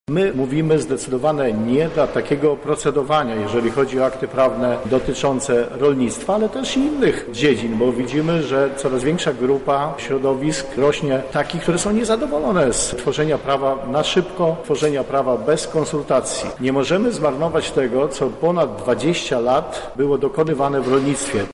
Na przygotowanie ustawy poświęcono zbyt mało czasu – mówi radny klubu PSL Grzegorz Kapusta: